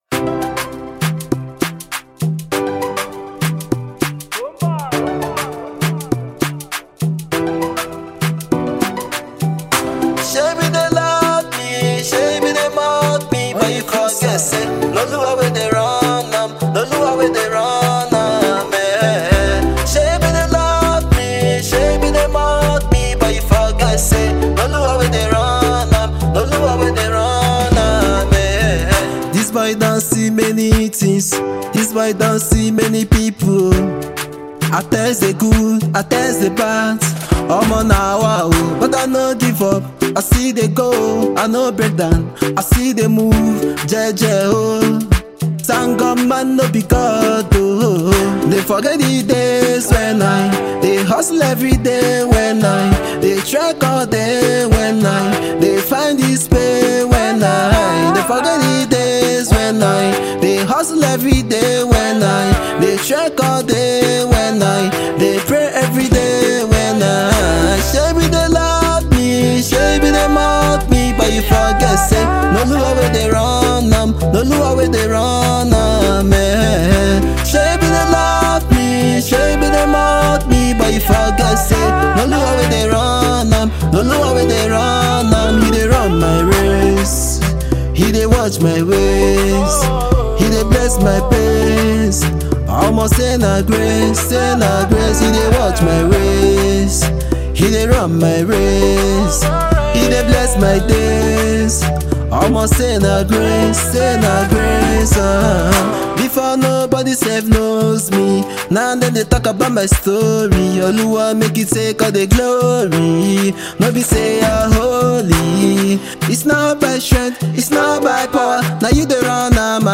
Young and talented Nigerian solo singer
studio single